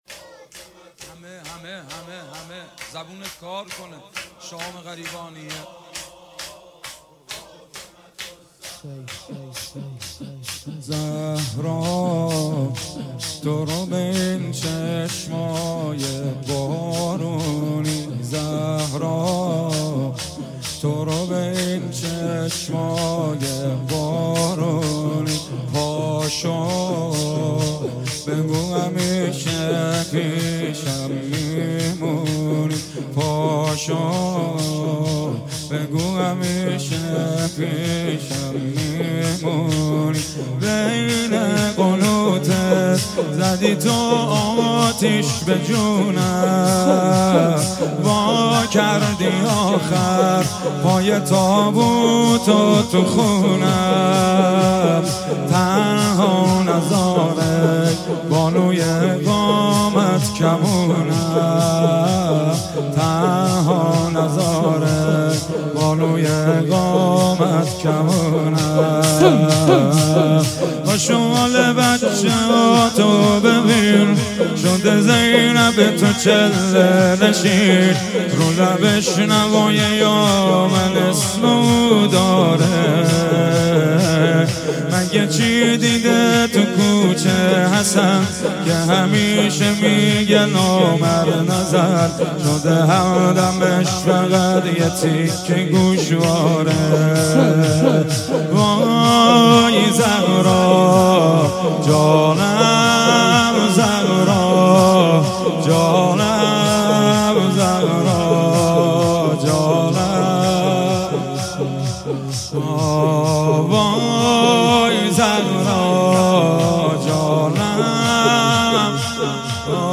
روضه انصارالزهرا سلام الله علیها
اقامه عزای روضه حضرت صدیقه شهیده علیها السلام _ شب اول